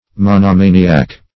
Monomaniac \Mon`oma"ni*ac\, n.